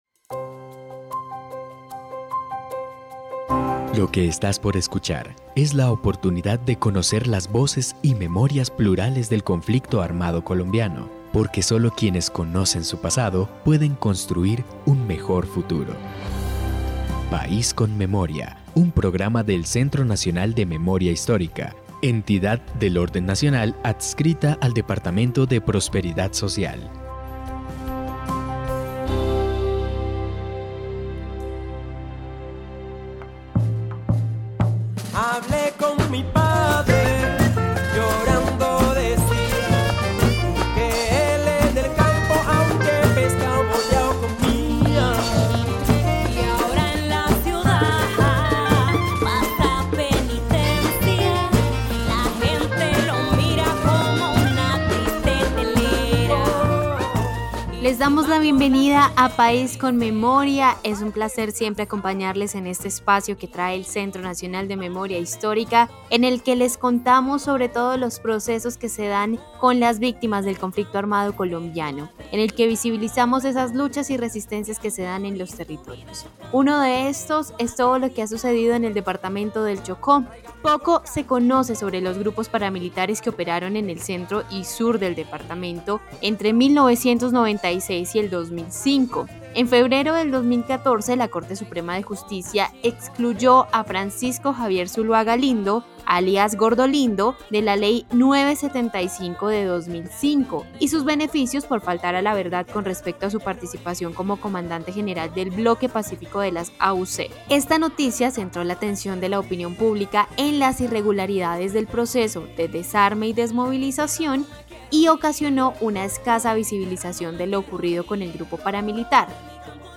Descripción (dcterms:description) Capítulo número 26 de la cuarta temporada de la serie radial "País con Memoria". Grupos paramilitares que operaron en el centro y sur del departamento del Chocó entre 1996 y 2005.